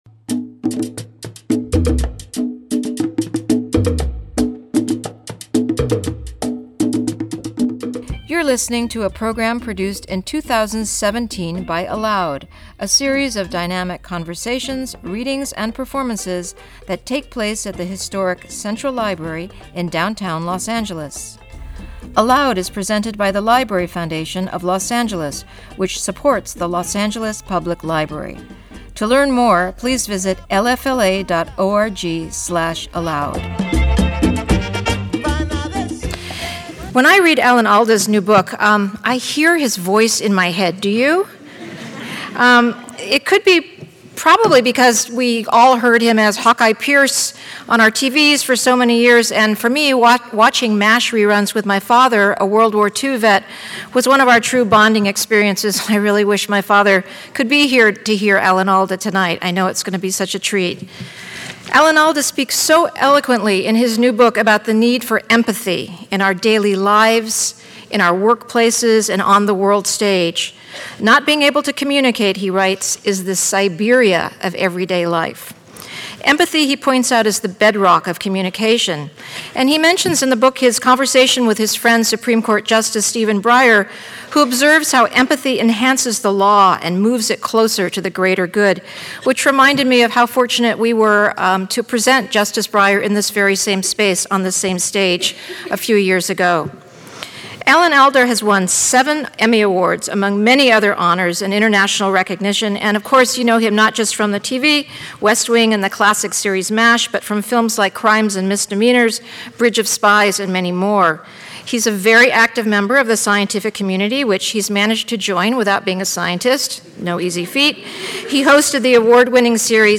Alan Alda, the award-winning actor and bestselling author, discusses his decades-long quest to understand the intricacies of communication.
In Conversation